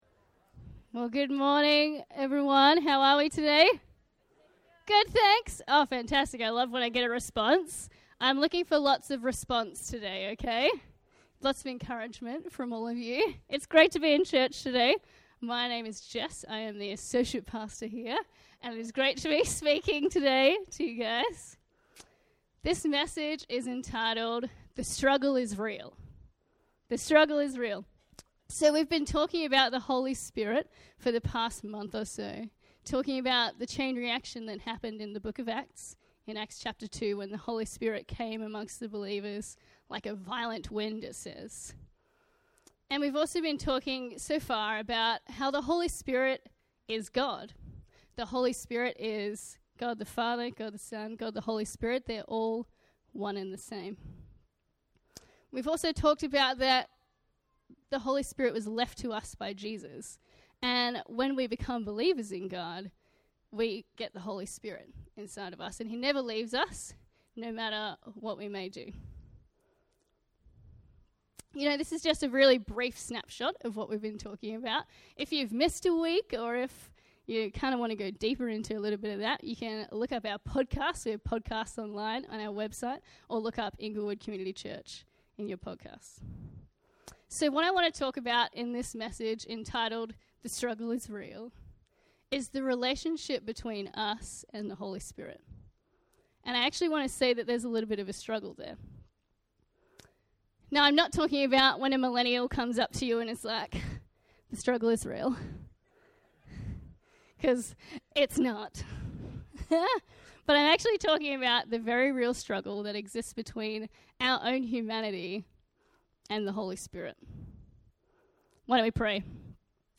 A message from the series "Easter."